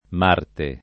m#rte] pers. m. mit. e n. pr. m. astron. — con m- minusc. quando sta per «martedì» (né di venere né di marte…) e, di rado, con sign. fig. («guerra») nell’uso poet.: le crudeli opre di marte [le krud$li 0pre di m#rte] (Leopardi) — cfr. marzio